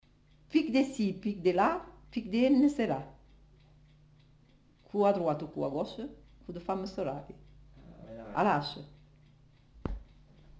Proverbe sur la femme
Aire culturelle : Comminges
Lieu : Cathervielle
Type de voix : voix de femme
Production du son : récité
Classification : proverbe-dicton